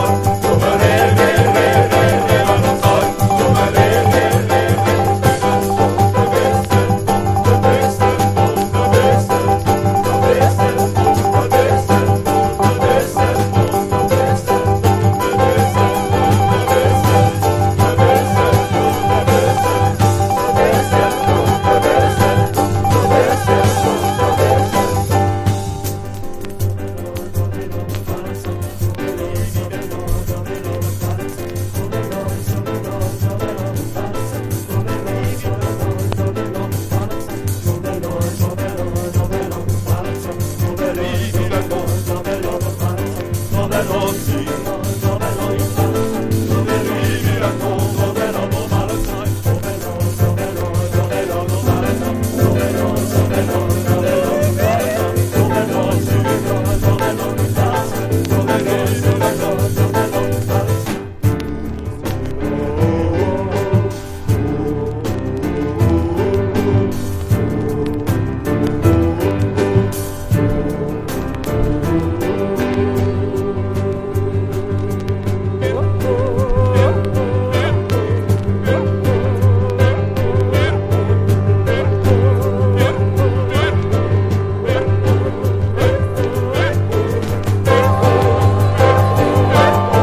重厚なリズム隊と呪術的な混声合唱の嵐。